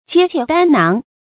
揭篋擔囊 注音： ㄐㄧㄝ ㄑㄧㄝ ˋ ㄉㄢ ㄉㄢ ㄉㄢ 讀音讀法： 意思解釋： 盜竊箱籠等財物。